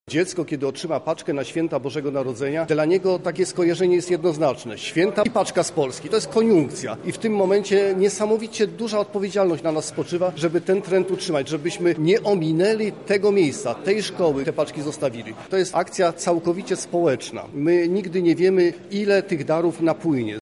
O znaczeniu zbiórki mówi senator RP Stanisław Gogacz, koordynator akcji: